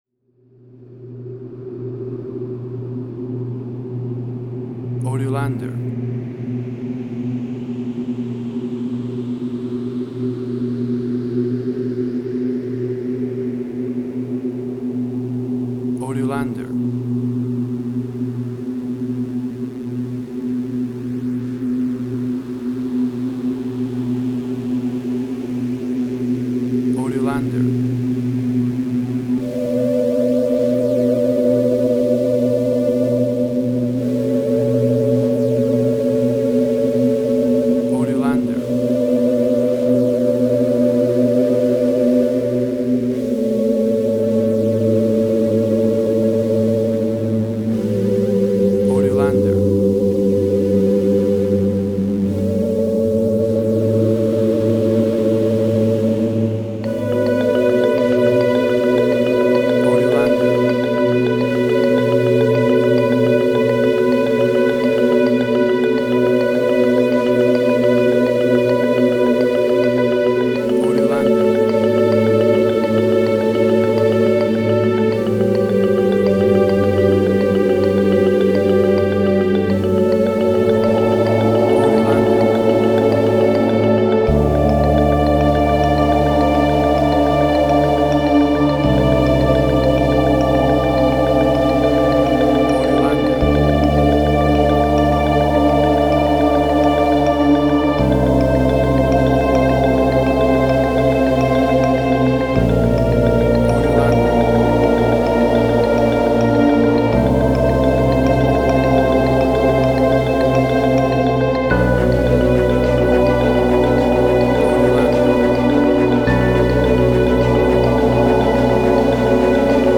New Age.
Tempo (BPM): 105